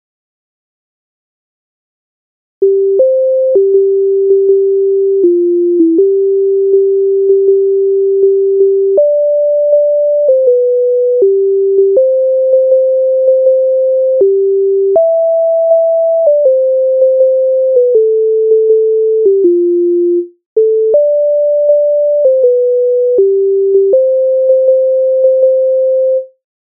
Лента а лентою Українська народна пісня повстанська
Ukrainska_narodna_pisnia_Lenta_a_lentoyu.mp3